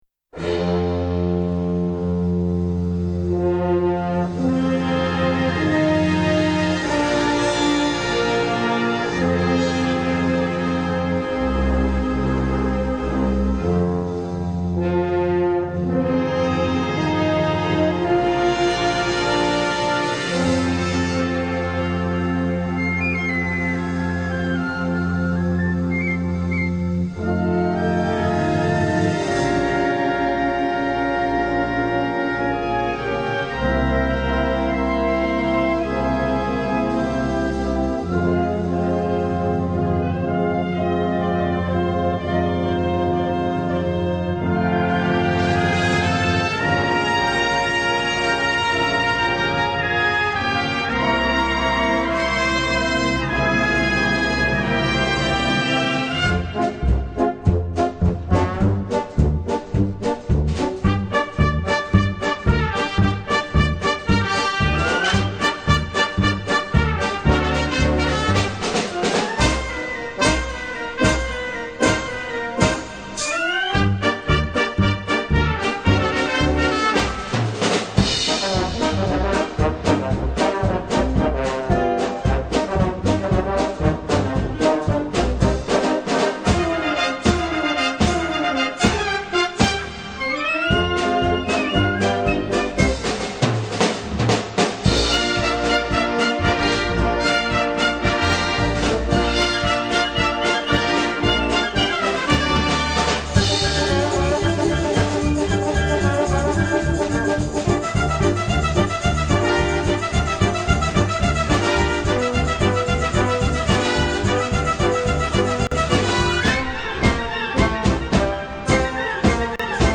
Ukrainische Volksmelodie Artikelnr.
Besetzung: Blasorchester